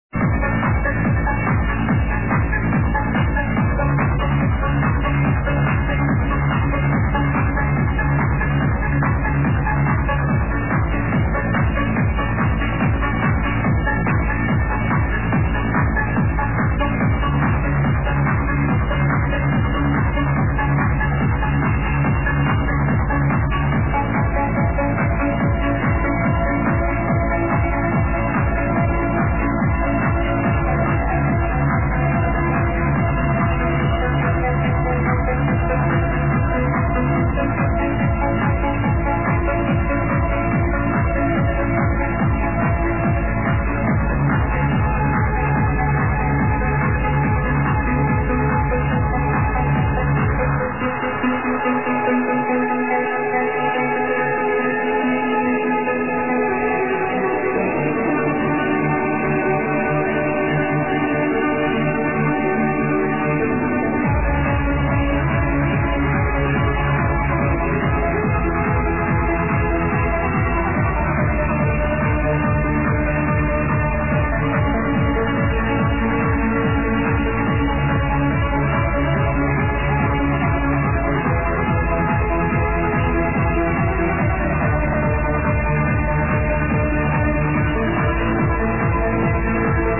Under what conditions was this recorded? btw sorry for quality